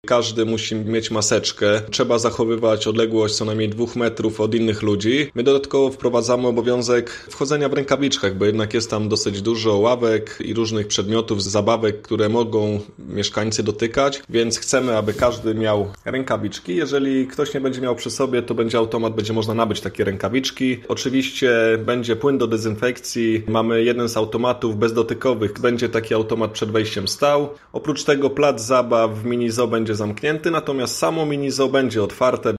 Mówi radny Paweł Wysocki: